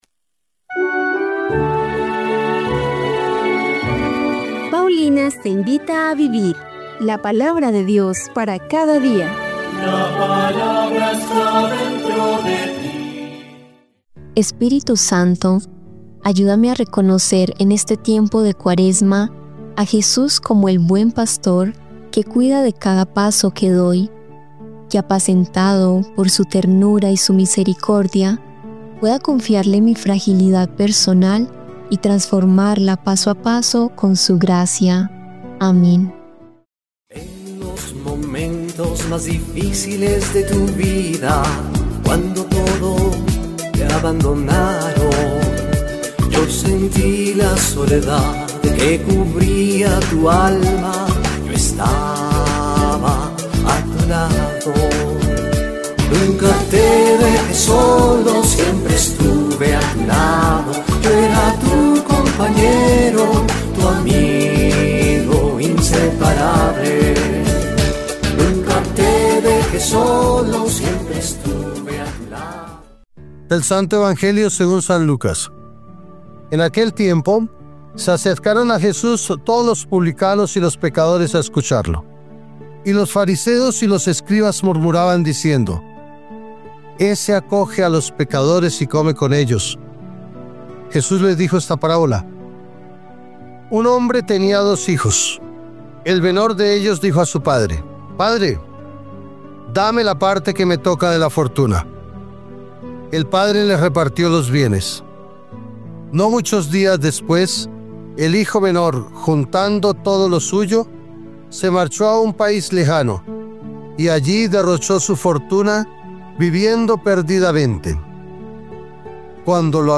Lectura del libro de Jeremías 20, 10-13